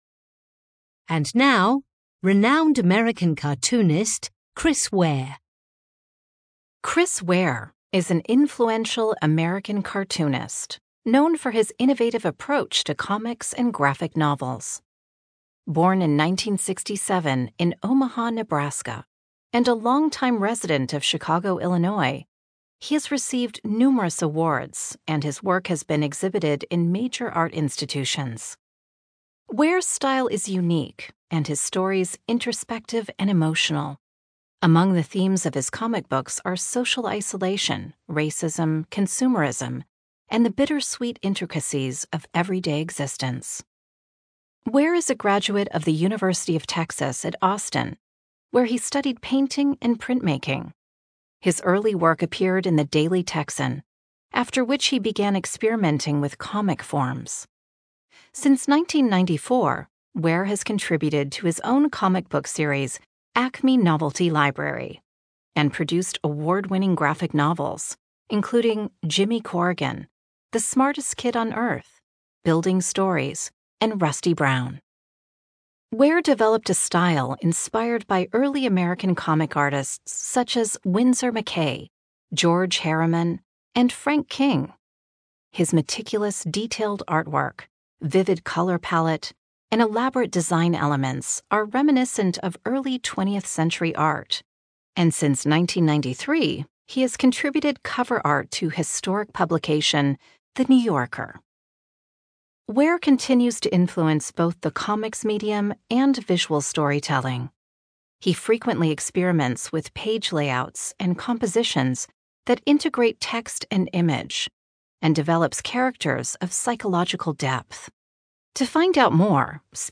Speaker (UK accent)